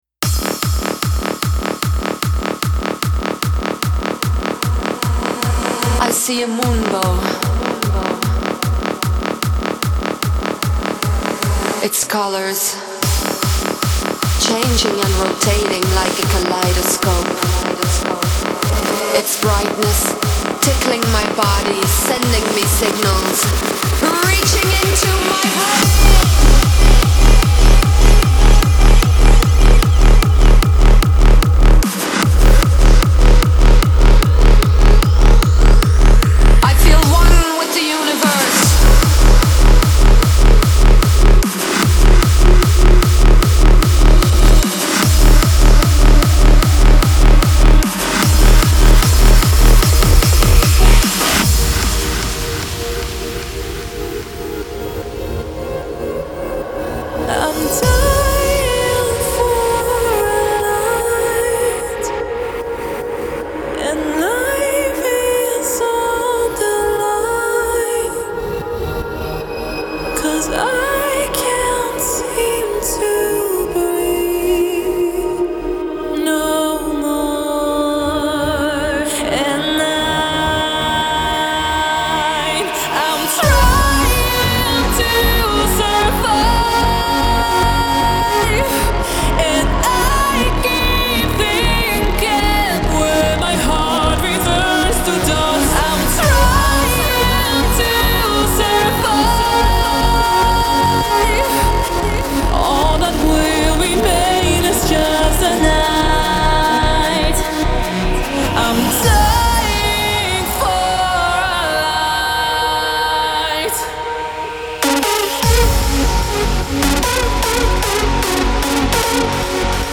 • Жанр: Hardstyle